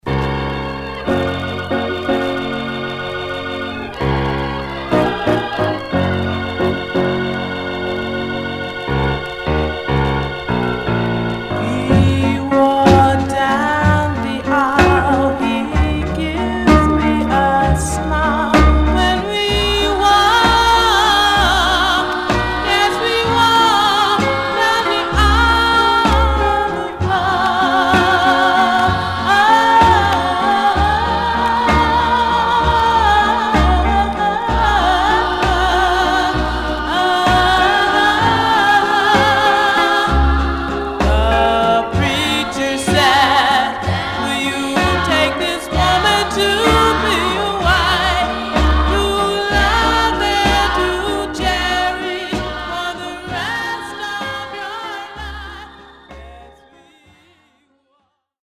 Some surface noise/wear
Mono
Black Female Group